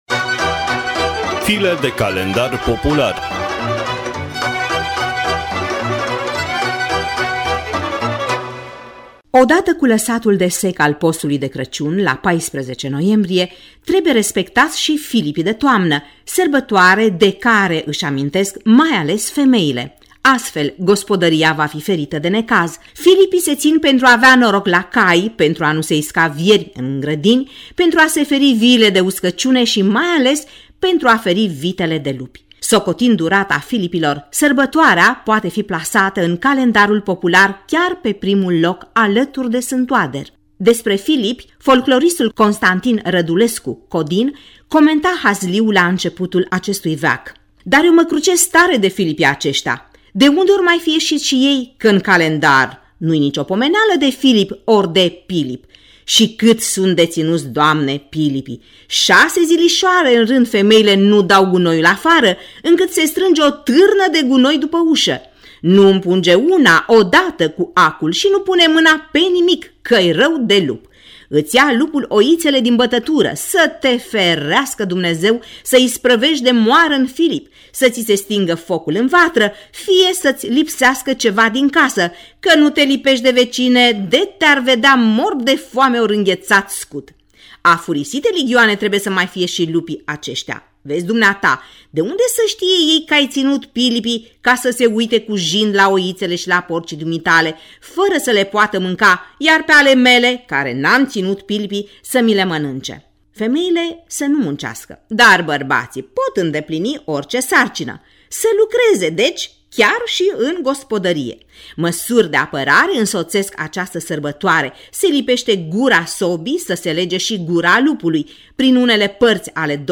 (varianta radiofonică a rubricii)